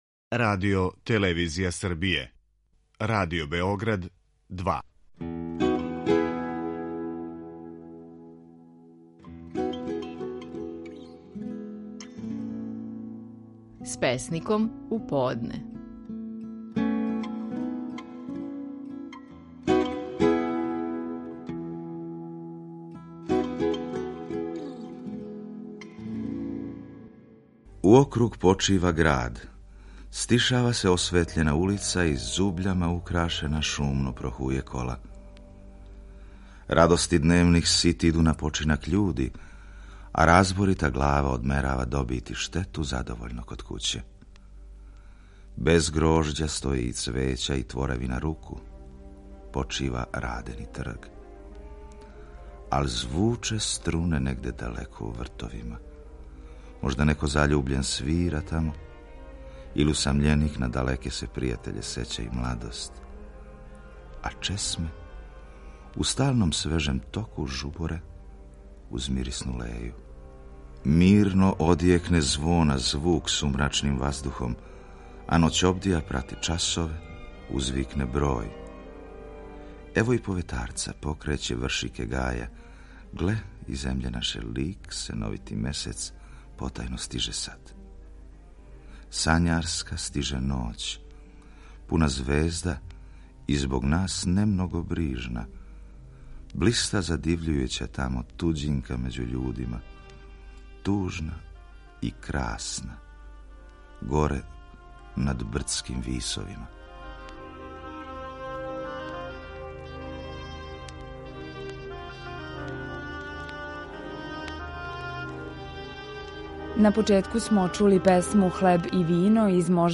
У Римовању нас очекује и део из композиције Рихарда Штрауса „Три химне", као и одломак из Хелдерлинове драме „Емпедоклова смрт".
преузми : 17.11 MB Римовање Autor: Група аутора У новој емисији посвећеној поезији, слушаоци ће моћи да чују избор стихова из Звучног архива Радио Београда које говоре најчувенији домаћи и инострани песници, драмски уобличене поетске емисије из некадашње серије „Вртови поезије", као и савремено стваралаштво младих и песника средње генерације.